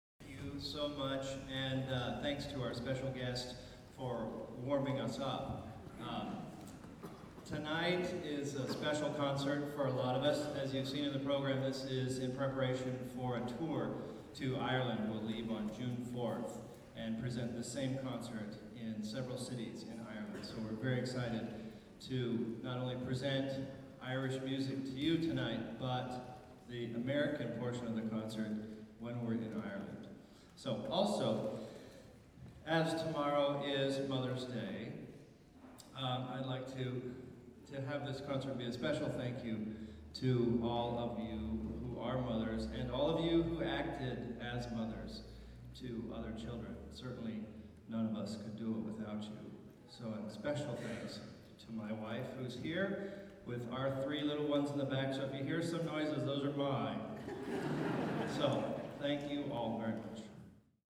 Santa Barbara City College Choir Concert, Spring 2008
Pre-Concert